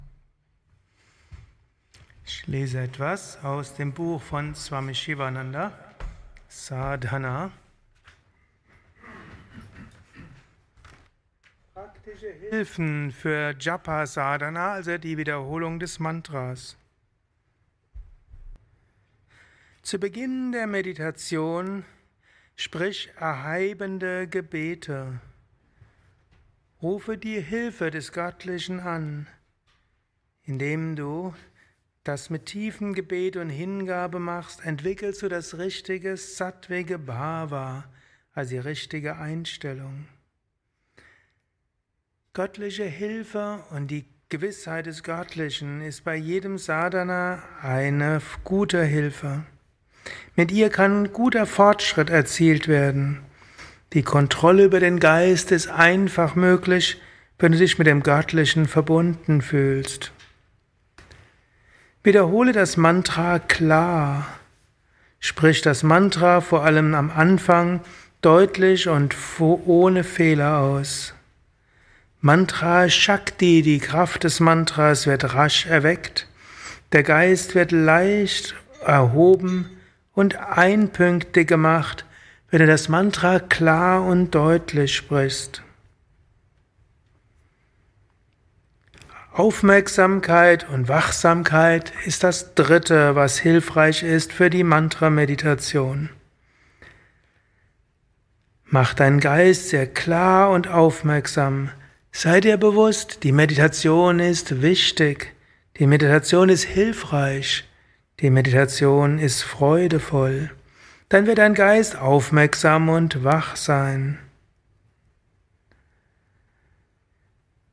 Satsangs gehalten nach einer Meditation im Yoga Vidya Ashram Bad